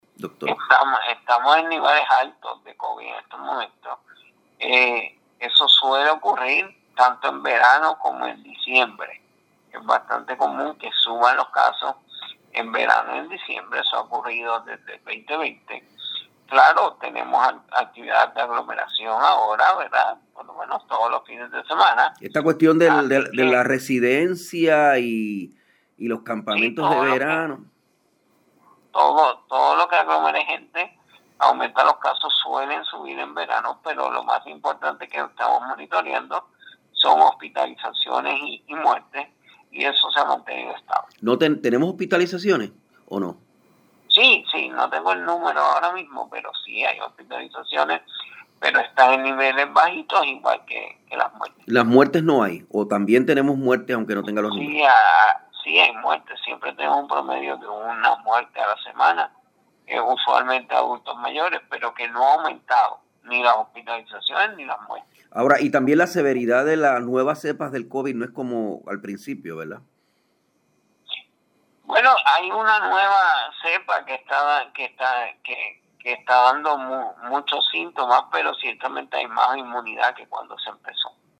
El secretario del Departamento de Salud, Víctor Ramos Otero, confirmó que se ha registrado un aumento en los niveles de contagio del coronavirus.